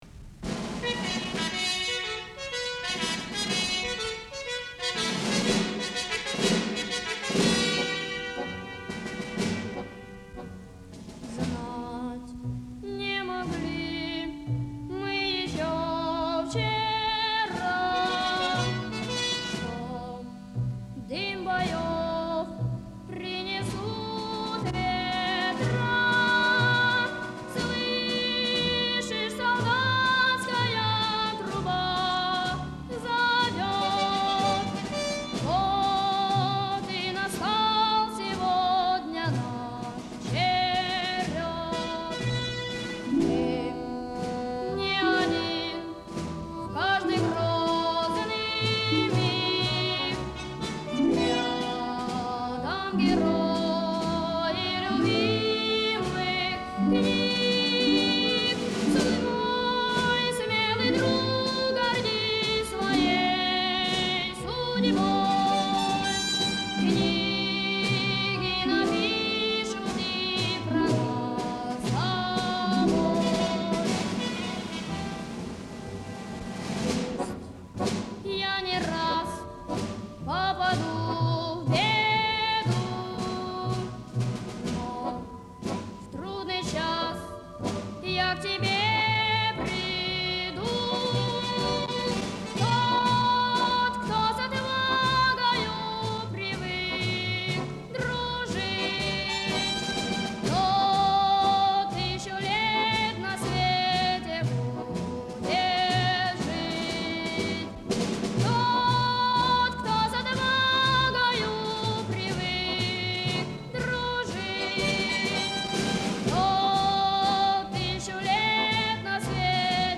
Из звуковой дорожки кинофильма